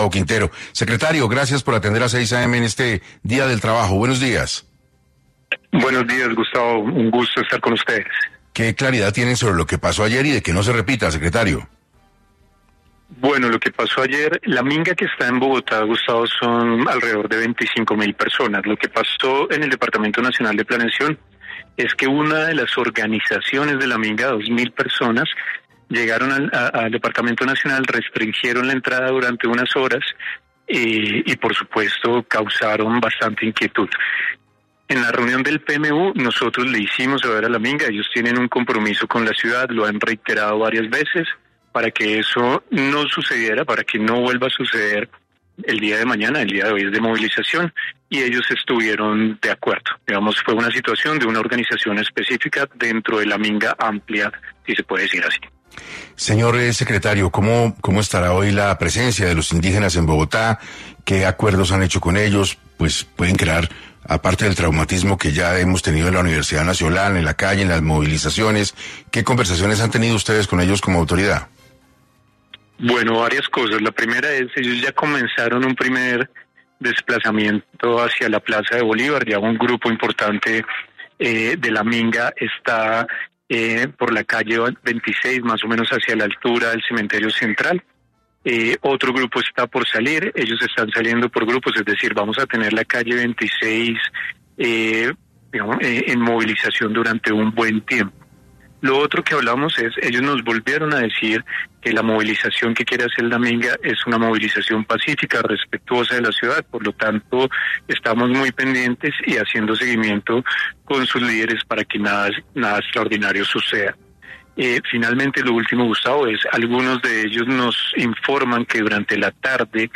El secretario de Gobierno de Bogotá, Gustavo Quintero, confirmó en 6AM de Caracol Radio que la Minga Indígena empezará a regresar a sus territorios durante hoy en la tarde.